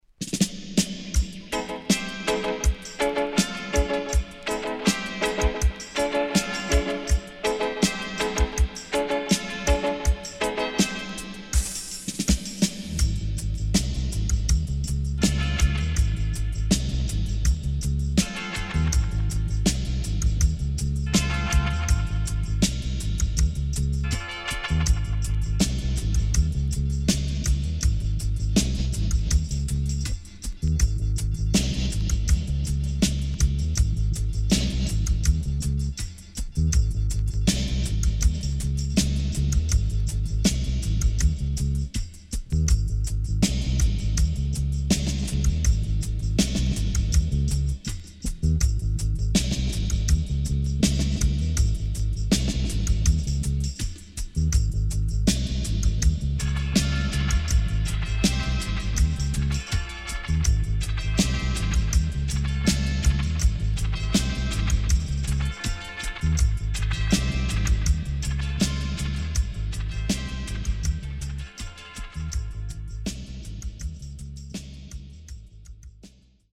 HOME > REISSUE [DANCEHALL]
Wicked Vocal